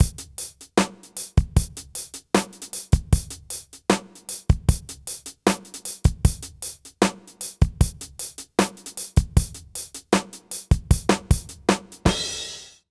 British REGGAE Loop 080BPM.wav